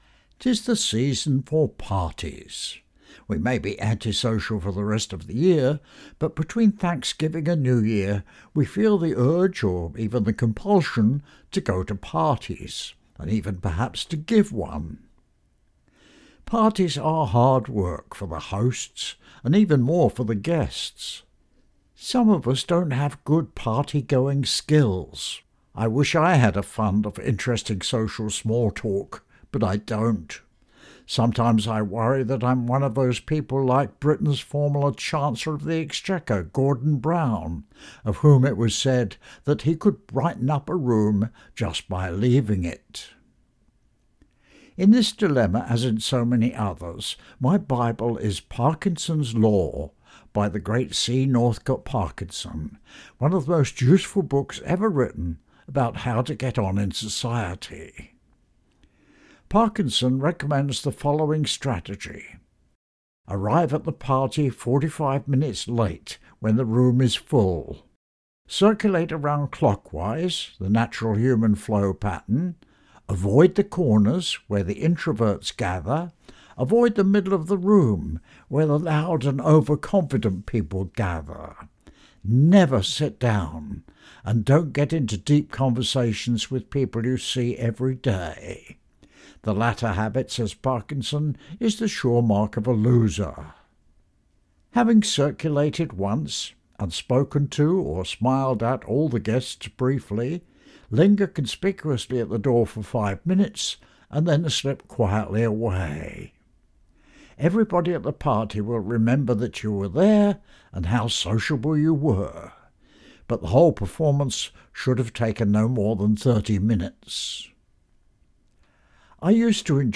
Commentator